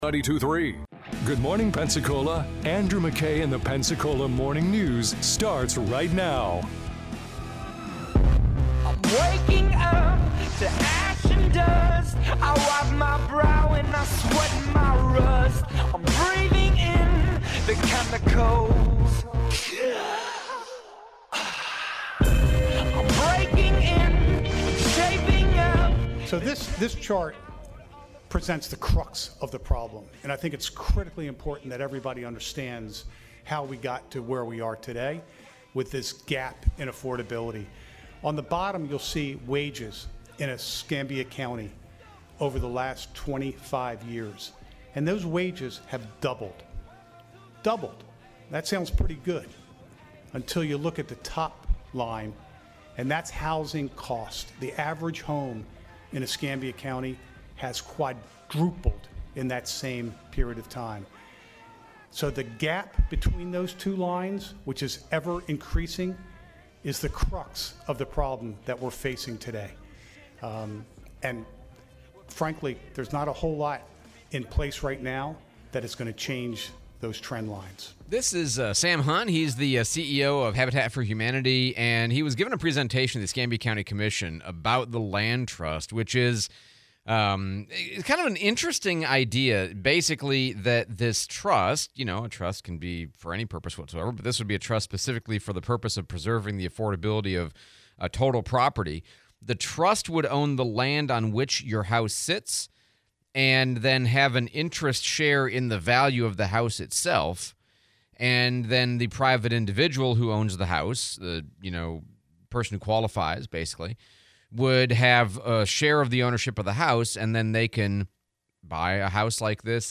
Pensacola Habitat CLT, interview with Congressman Patronis